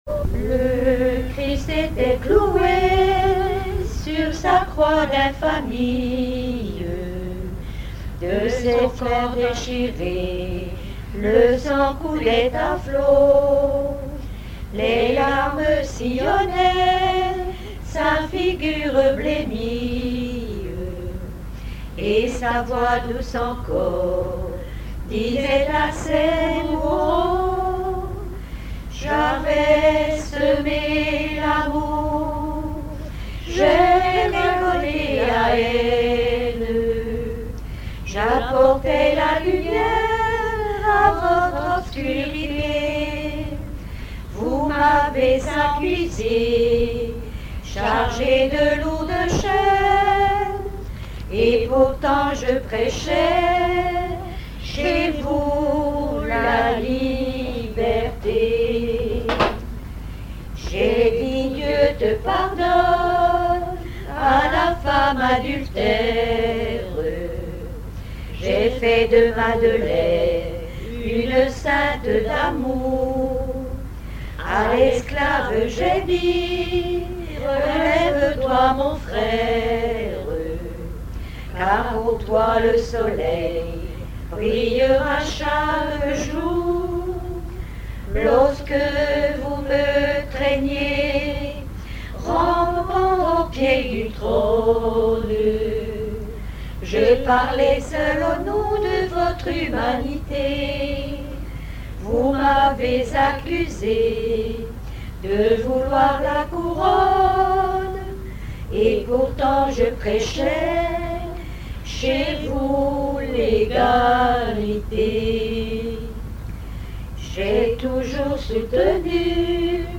circonstance : dévotion, religion
Genre strophique
Pièce musicale inédite